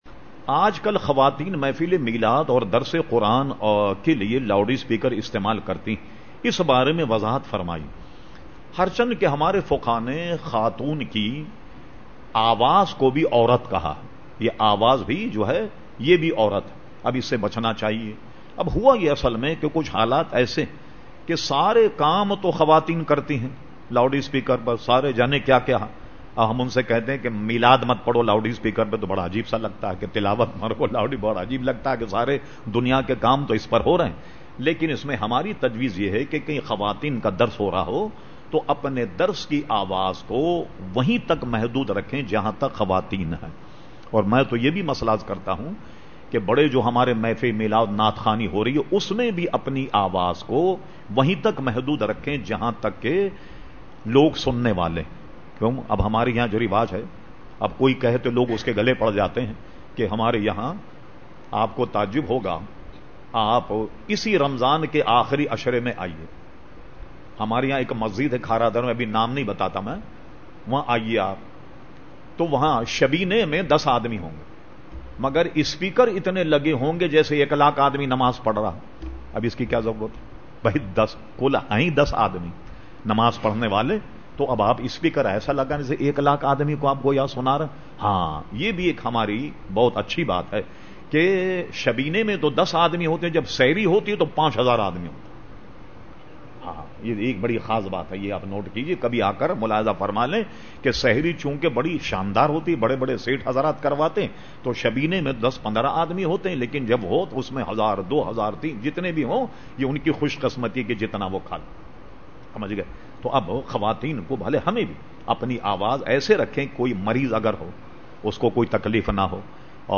Q/A Program held on Sunday 29 August 2010 at Masjid Habib Karachi.